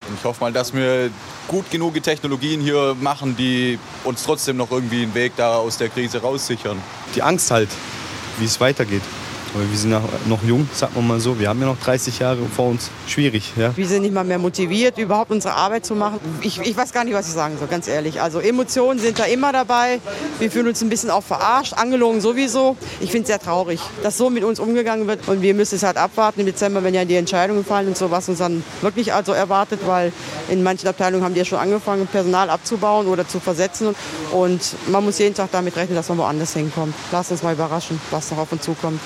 Eine Bosch-Angestellte, die am Montag in Reutlingen demonstriert hat.